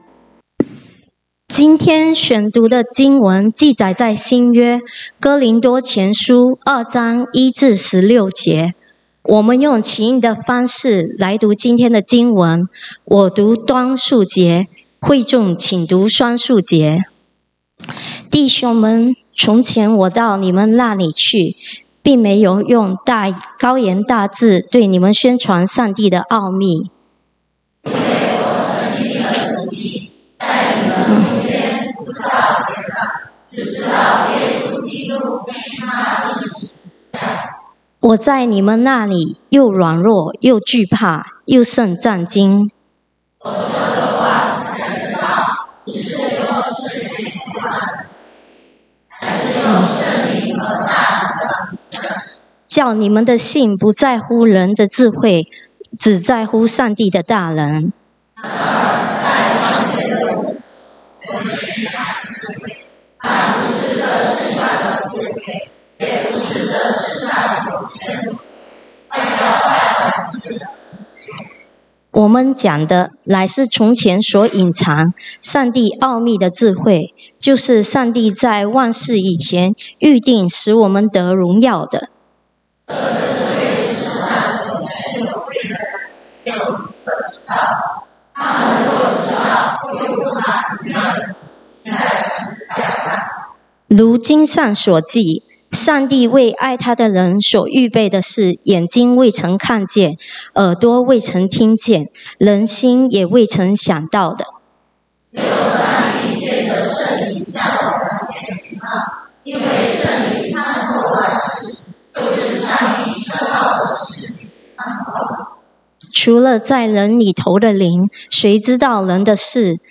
講道經文：哥林多後書 2 Corinthians 2:1-16